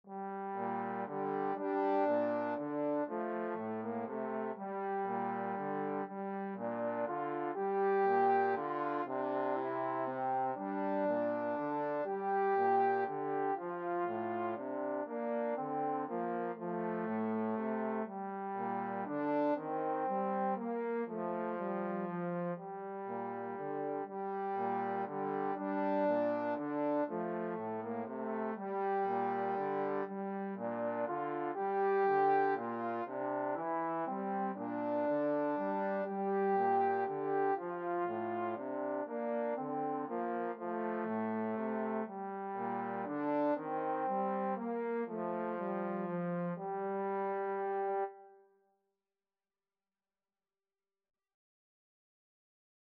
Trombone 1Trombone 2
Lento =120
3/4 (View more 3/4 Music)